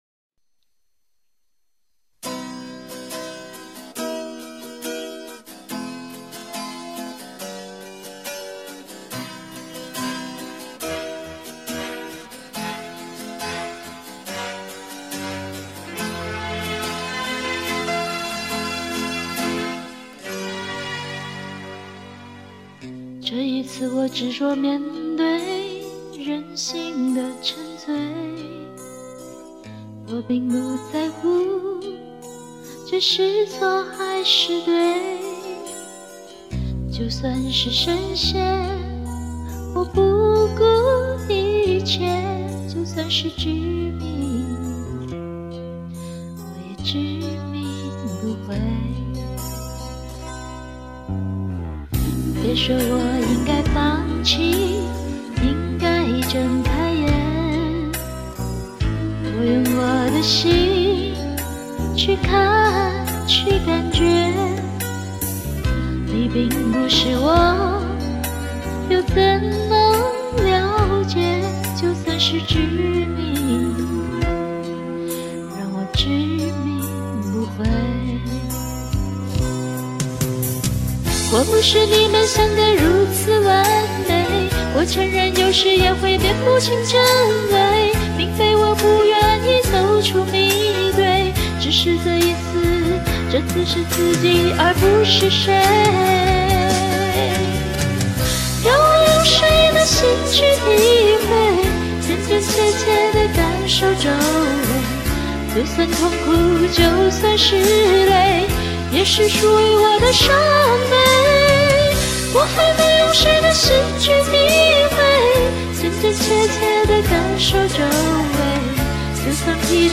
• 中气略显不足，望每日跑步五公里，三月见效。